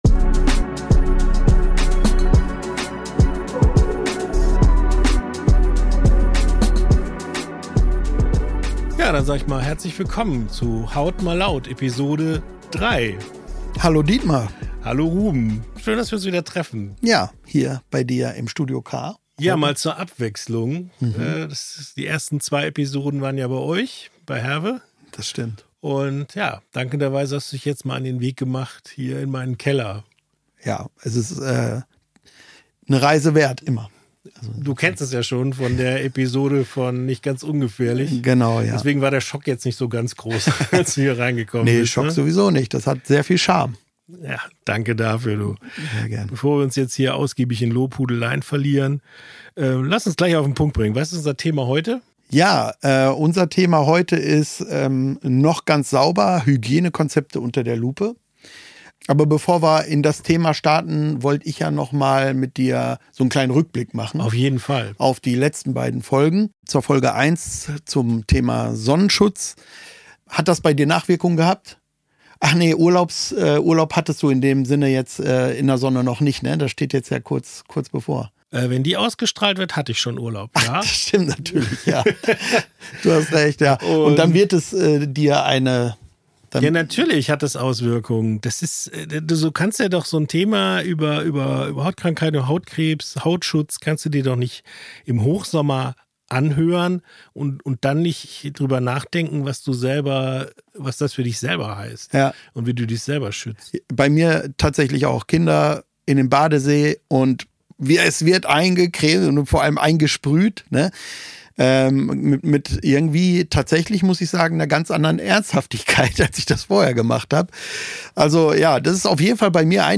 Ein entspannter Talk über ein Thema, das uns alle betrifft – die Hygiene!